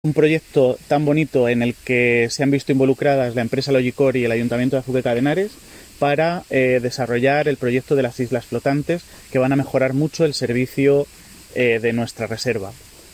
Declaraciones del alcalde Miguel Óscar Aparicio